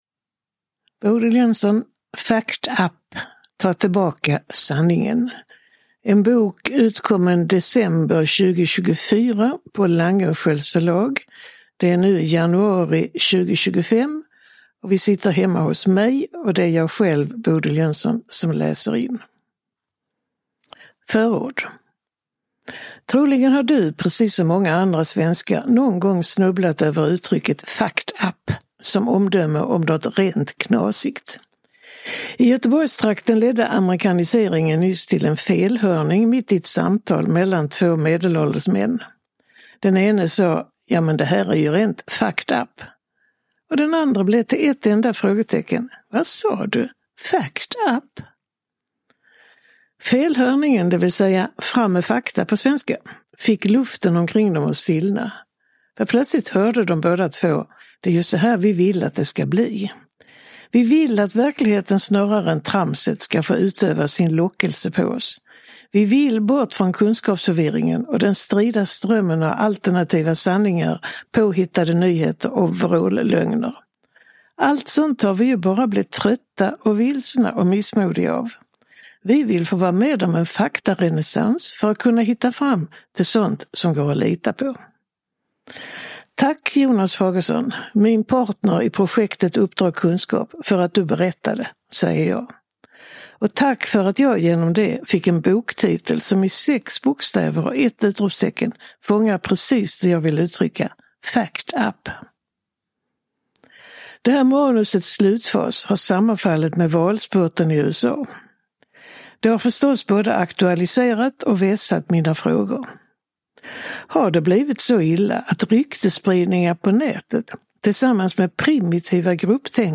Uppläsare: Bodil Jönsson
Ljudbok